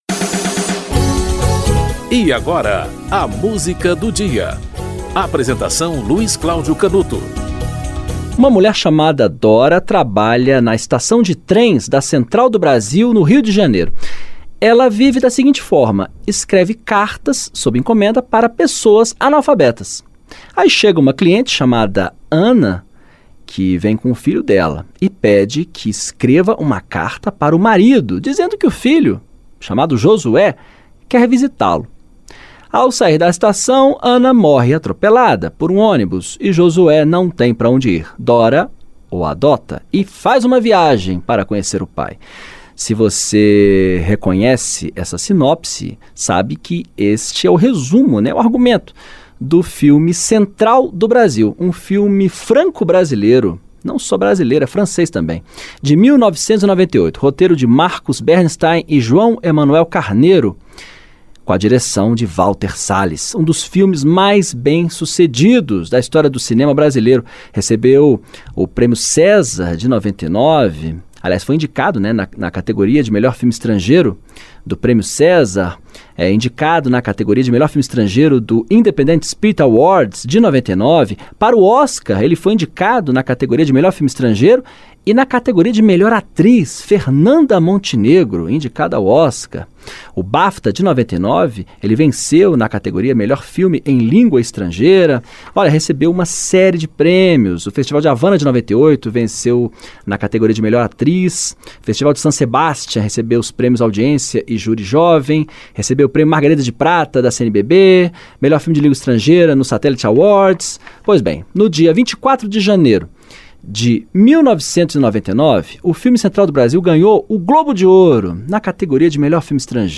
Jaques Morelembaum - Tema de Central do Brasil (Antonio Pinto e Jaques Morelembaum)
O programa apresenta, diariamente, uma música para "ilustrar" um fato histórico ou curioso que ocorreu naquele dia ao longo da História.